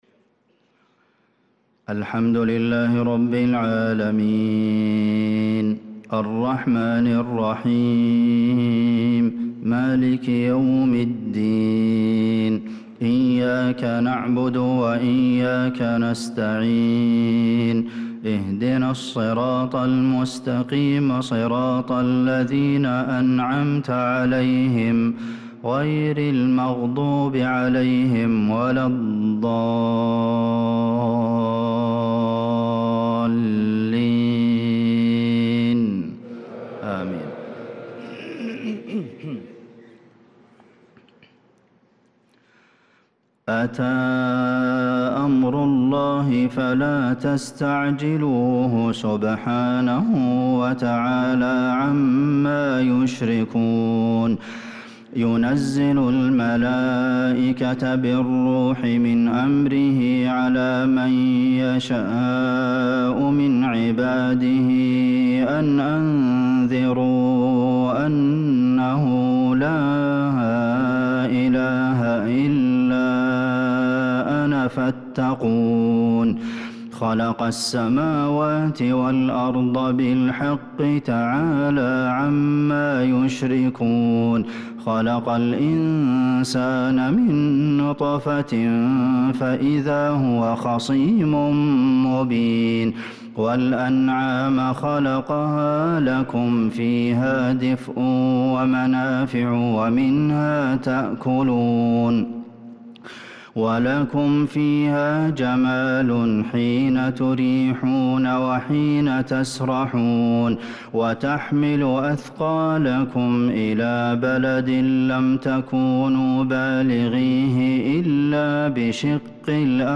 صلاة العشاء للقارئ عبدالمحسن القاسم 24 شوال 1445 هـ